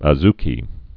(ə-zkē)